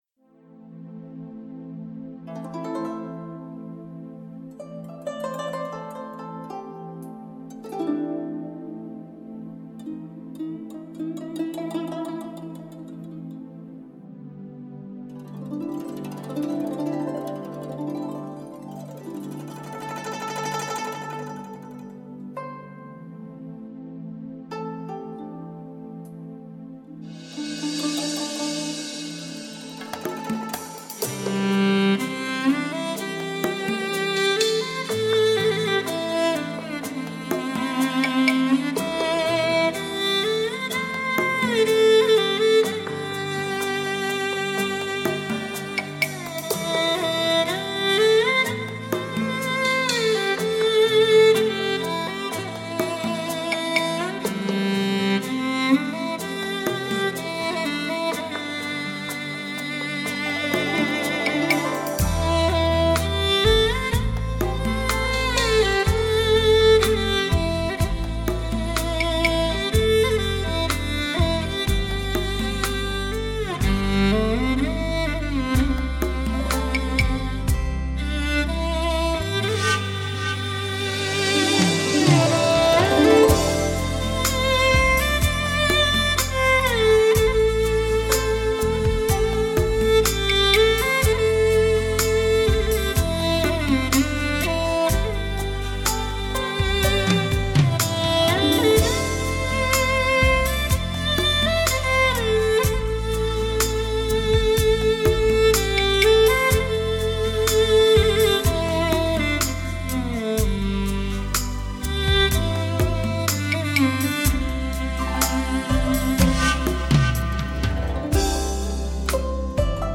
HQ Hi Quality CD
音色绝美乐曲悦耳动听而不俗